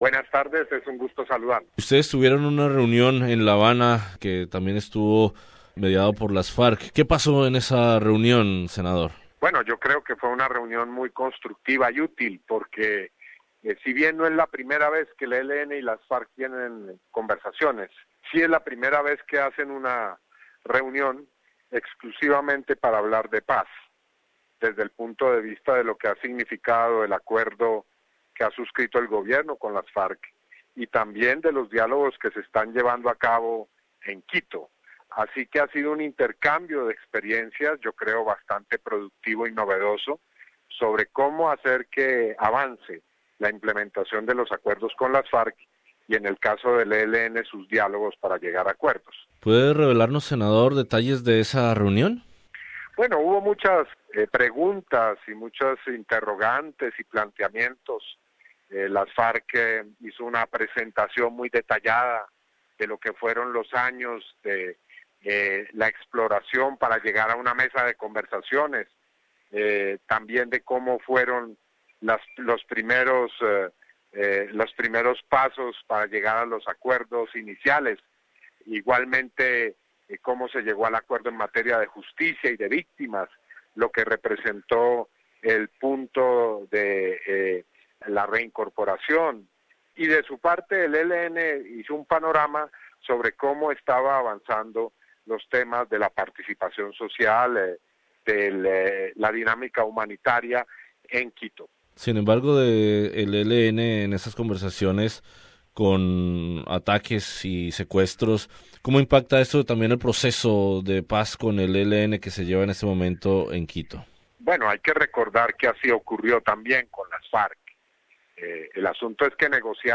El gobierno de Colombia y la guerrilla del Ejército de Liberación Nacional (ELN) reiniciaron esta semana en Quito los diálogos que buscan superar medio siglo de conflicto armado, en un segundo ciclo de conversaciones en el que esperan concretar una reducción de la violencia. La semana pasada los jefes de las FARC y el ELN discutían en Cuba la opción de alcanzar una "paz completa" para Colombia, en un encuentro autorizado por el presidente Santos y en el que estuvo presente el senador del Polo Democrático Iván Cepeda, quien conversa en Hora 13 sobre la reunión en la Habana y sobre las conversaciones con el ELN en Quito.